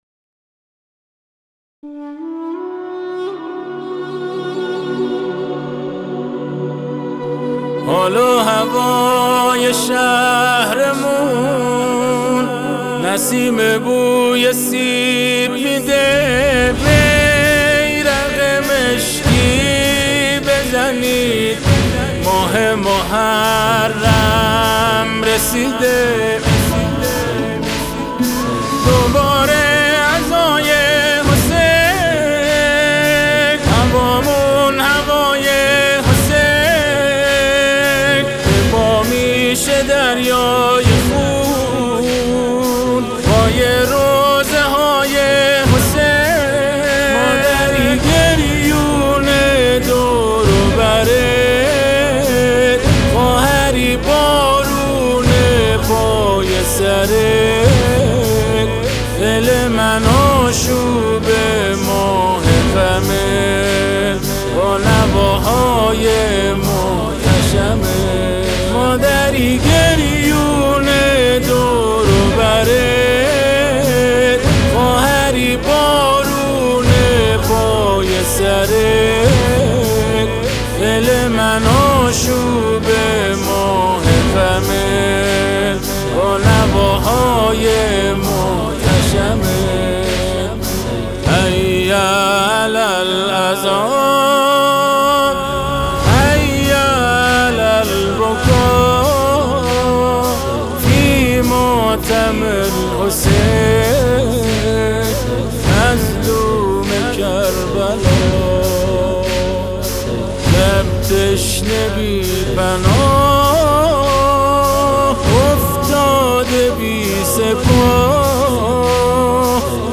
اثر آوایی